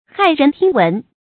注音：ㄏㄞˋ ㄖㄣˊ ㄊㄧㄥ ㄨㄣˊ
駭人聽聞的讀法